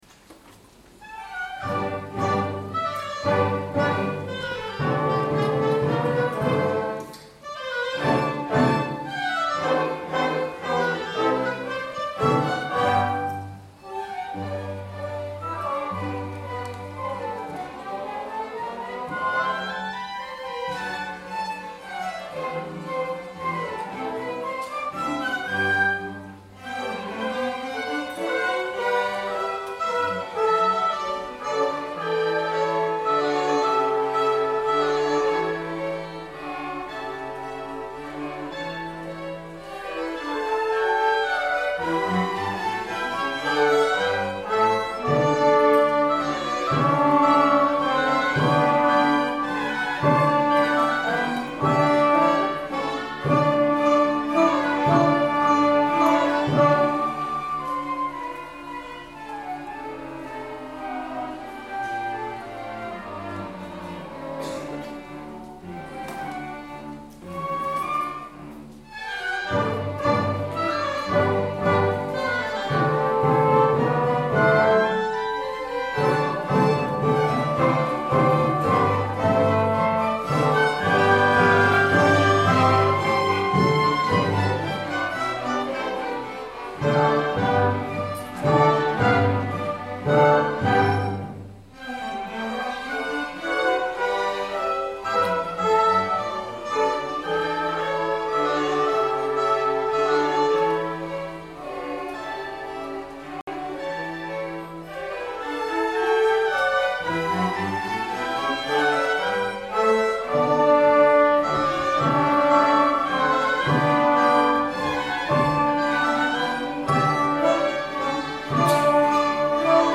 Orchestra 1